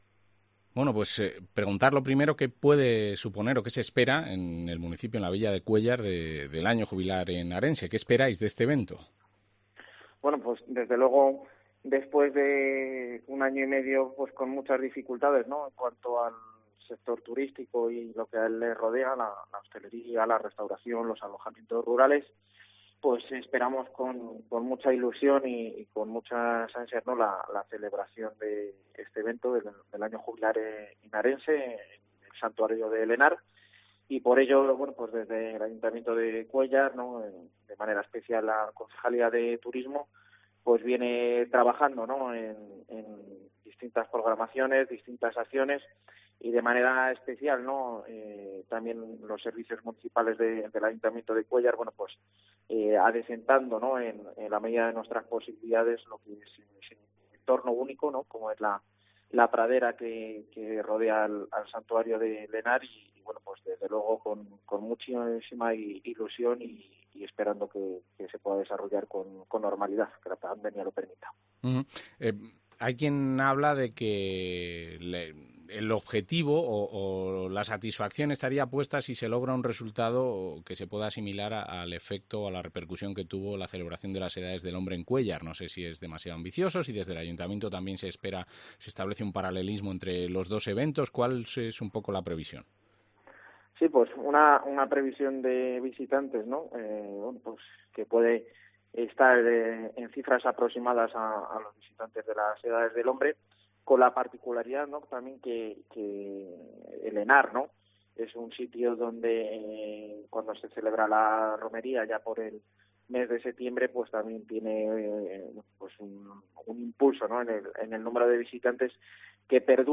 Carlos Fraile, alcalde de Cuéllar, sobre el Año Jubilar Henarense